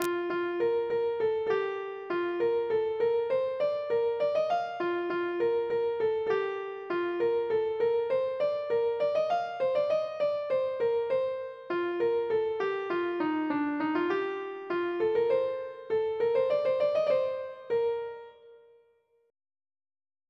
Air.